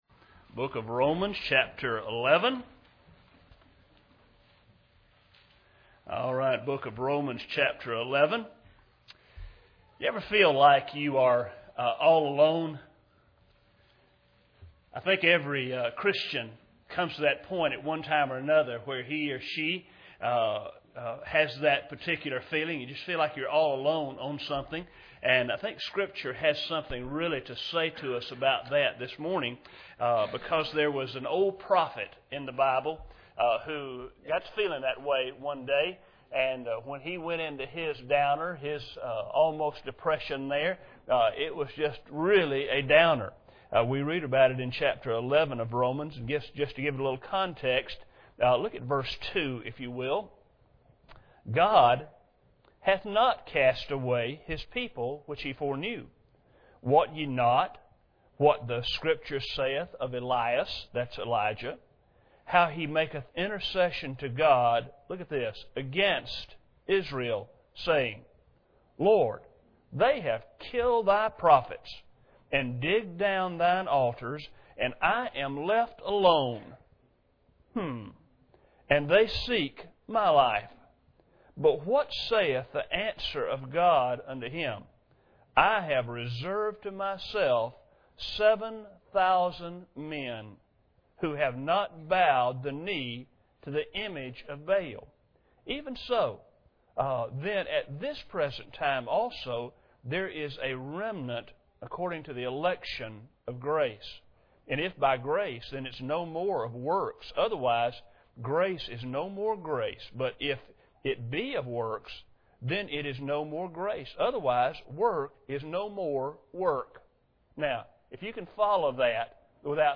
Romans 11:2-6 Service Type: Sunday Morning Bible Text